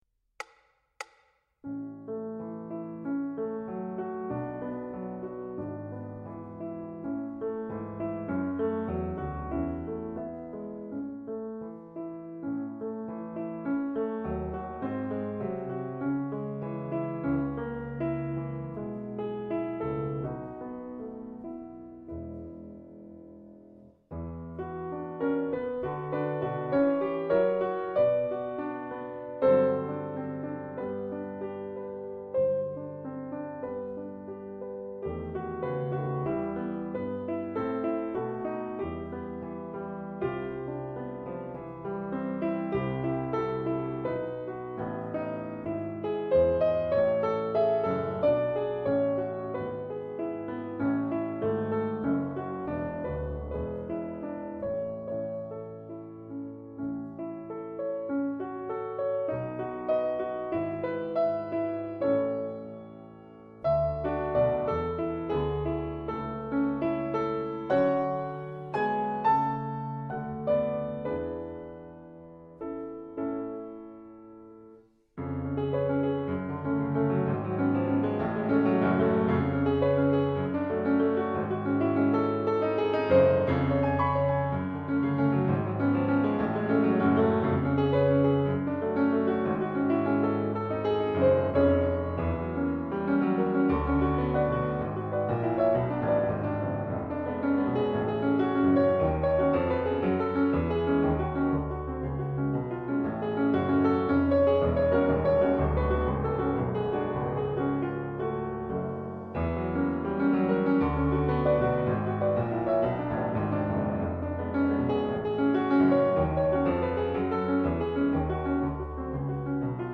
2nd SchumannRomance Piano Backing